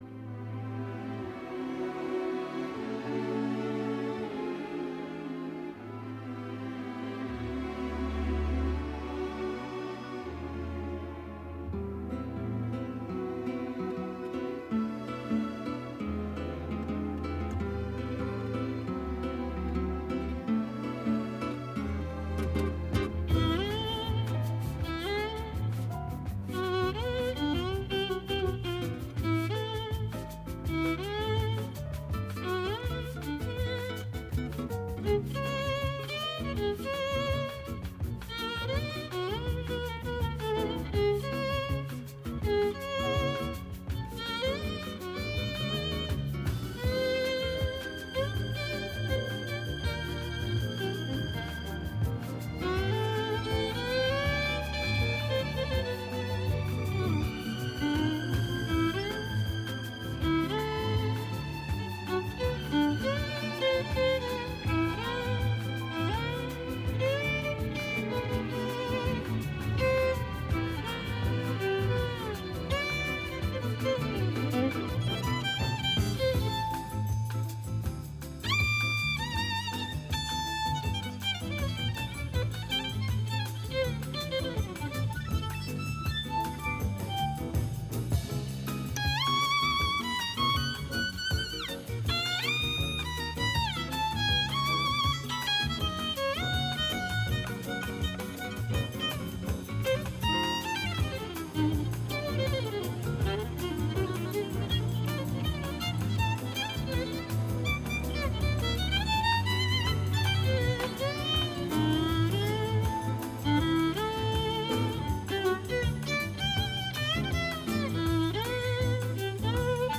Η μουσική συνοδεύει, εκφράζοντας το «ανείπωτο».